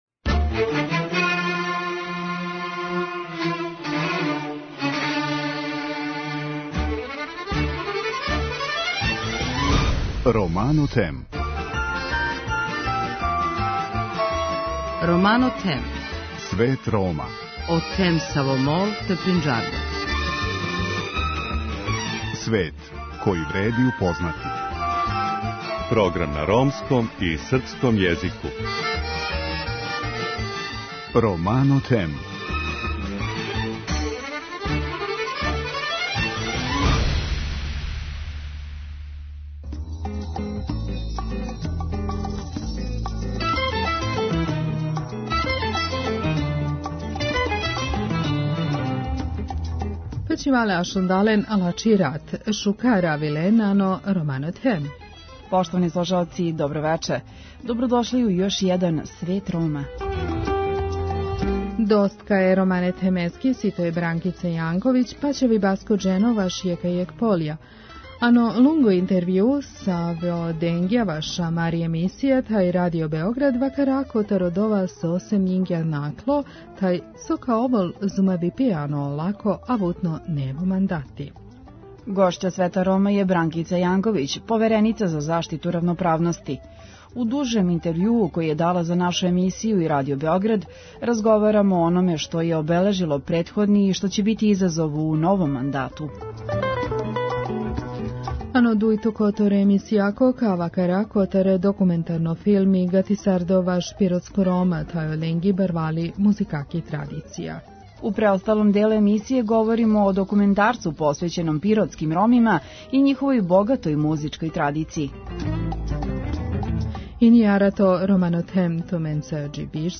Гошћа Света Рома је Бранкица Јанковић, повереница за заштиту равноправности. У интервјуу који је дала за нашу емисију, сумира досадашњи рад и говори о ономе што ће, како оцењује, бити изазов у новом мандату.